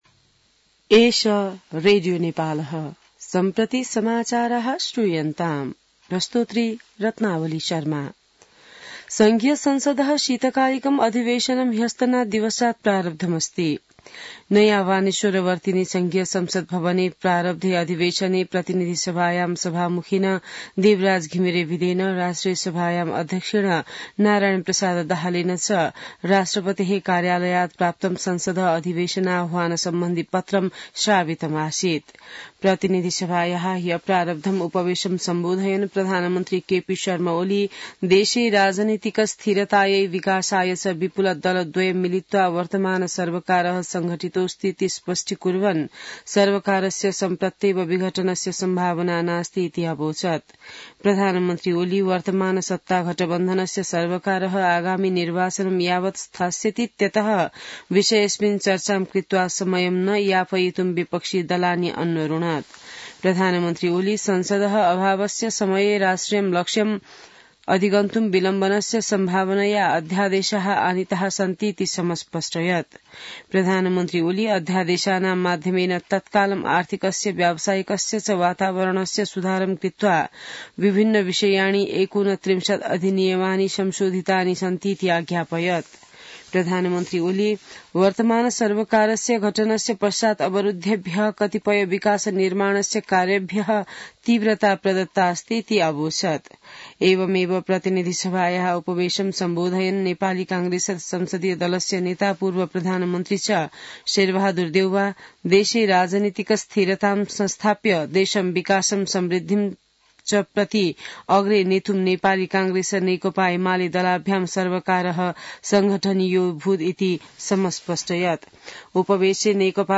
संस्कृत समाचार : २० माघ , २०८१